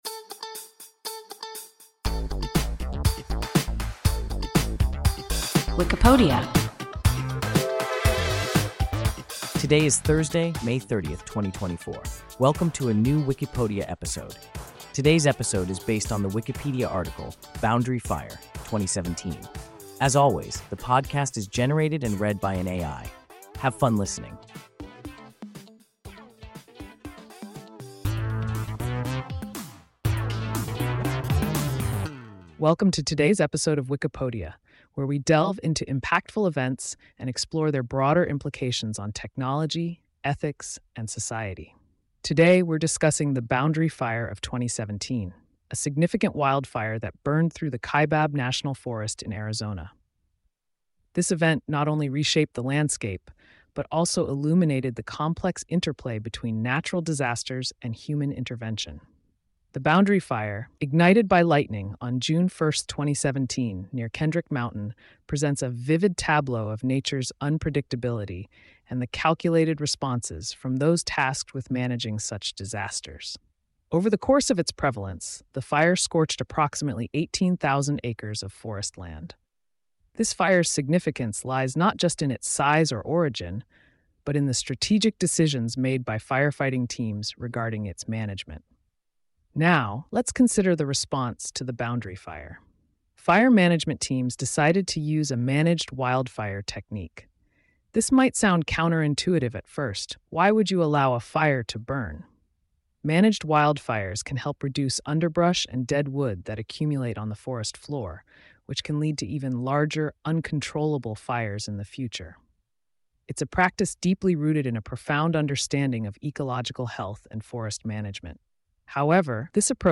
Boundary Fire (2017) – WIKIPODIA – ein KI Podcast